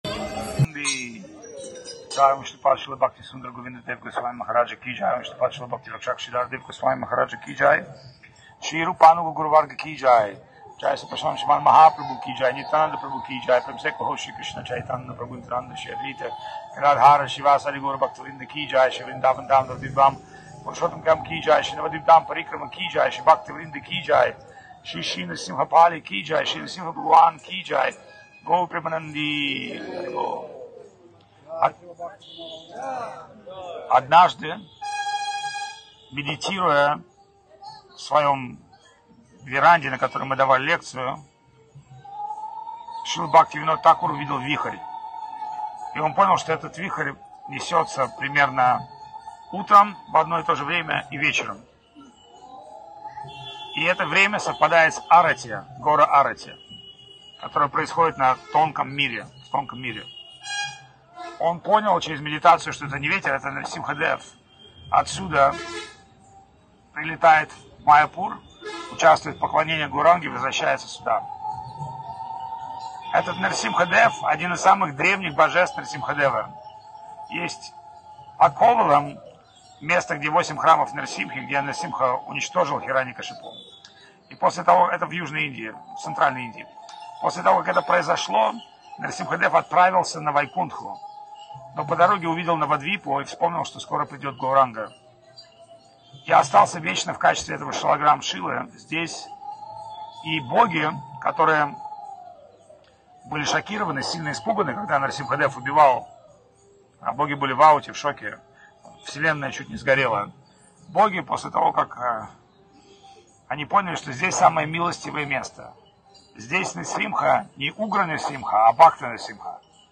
Место: Нрисимха-пали (Западная Бенгалия, Индия)
Фрагменты лекций
Бхаджан
Киртан